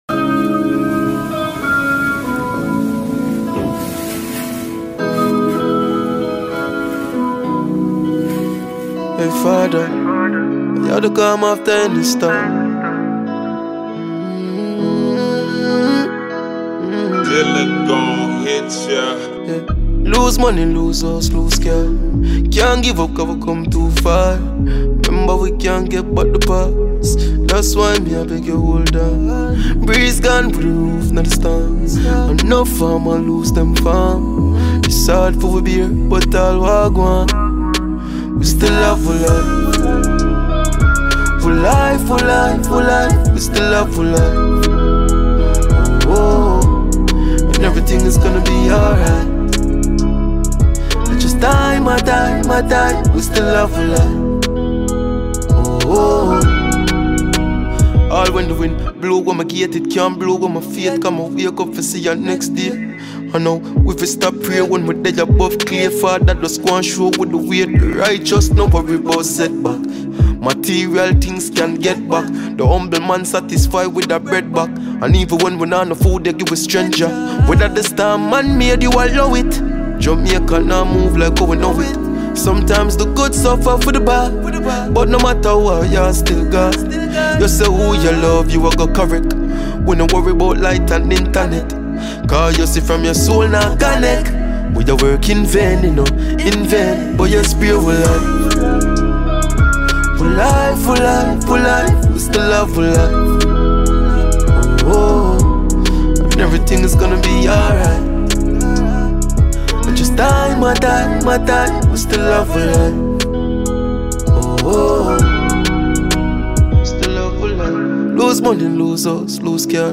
Jamaican reggae and dancehall
carries a warm and steady rhythm
smooth vocals and deep lyrical delivery